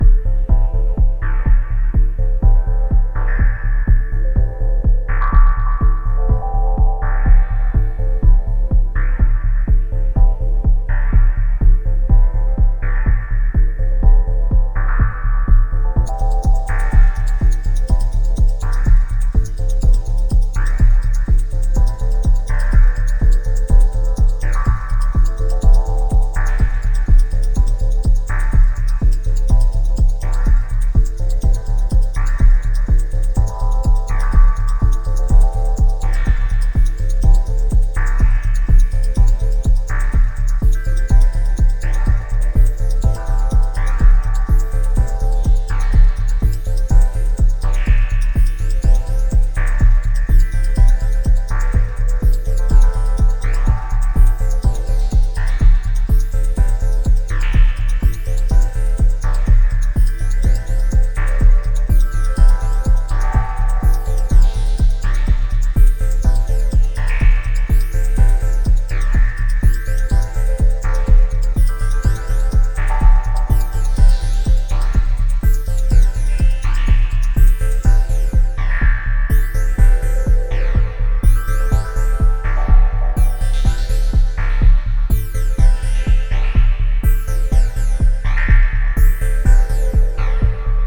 持続する低音ドローンベースとオルゴールを思わせるメインリフに痺れる124BPMのハウスチューンA2。